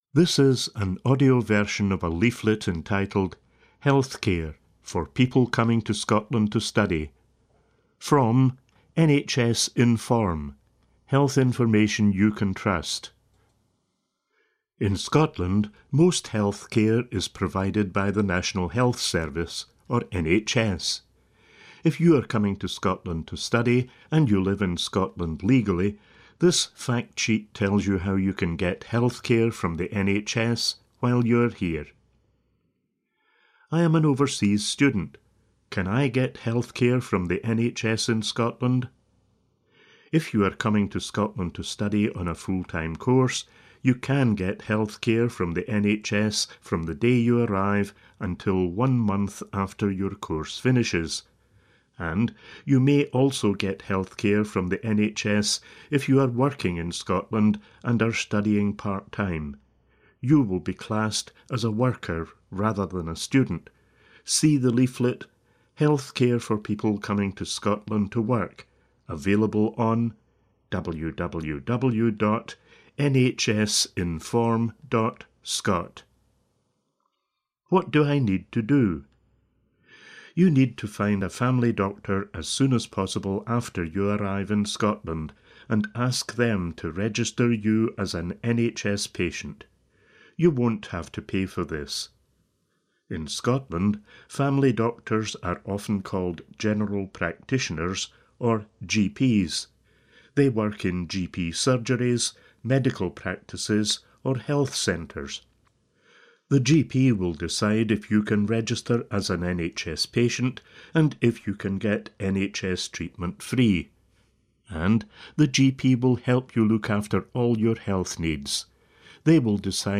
If you're an overseas student living legally in Scotland, this audio factsheet explains how you can get healthcare from the NHS while you're here.